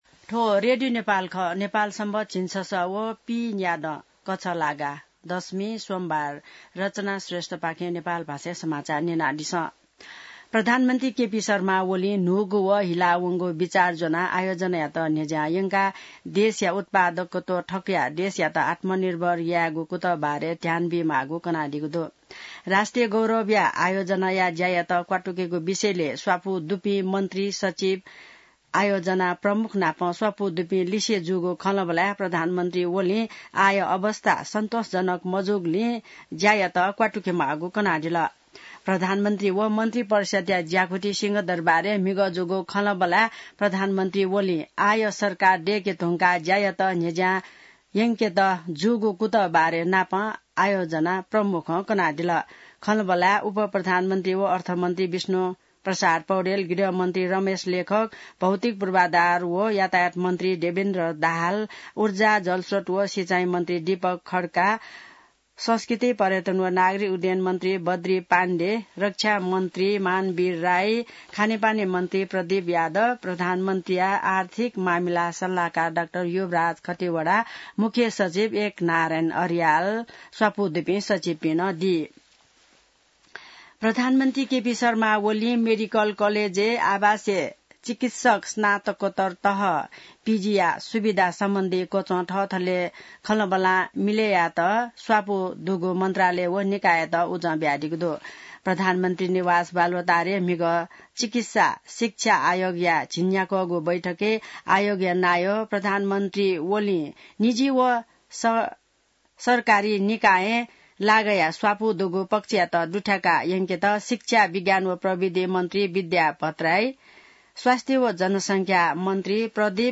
नेपाल भाषामा समाचार : ११ मंसिर , २०८१